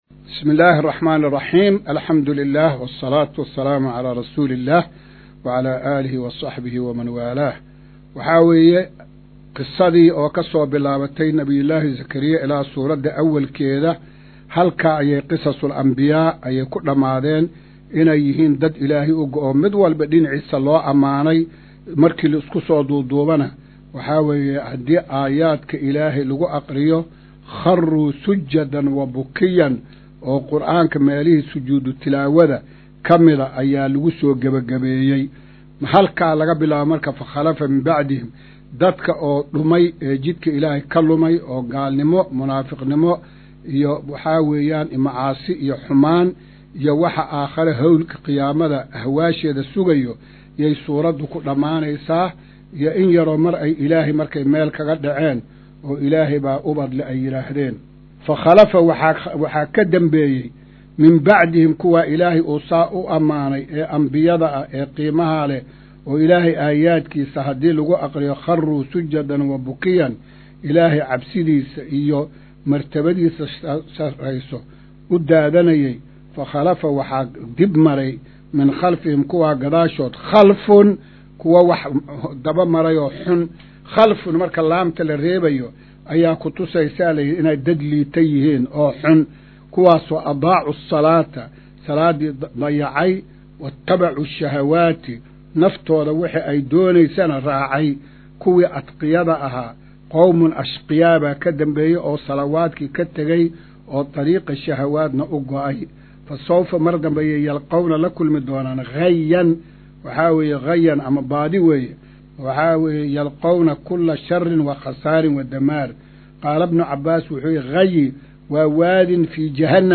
Maqal:- Casharka Tafsiirka Qur’aanka Idaacadda Himilo “Darsiga 150aad”